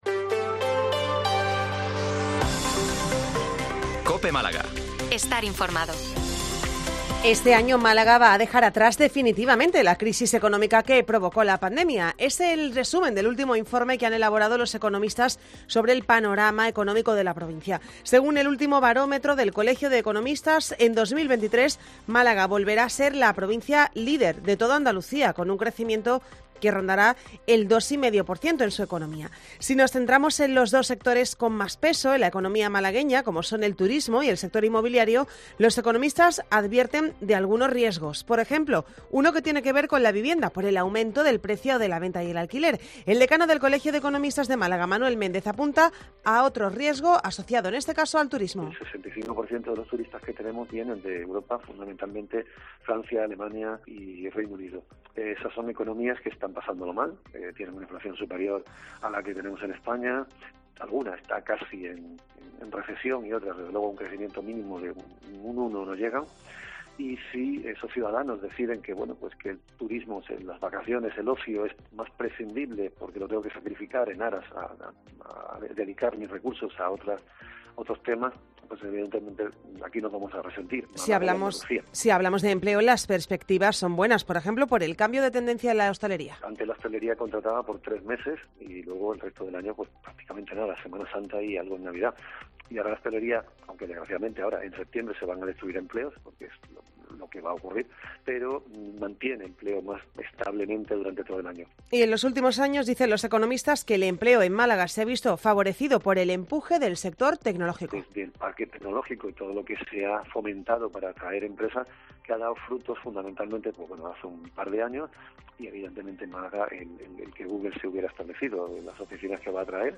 Informativo 14:20 Málaga 130923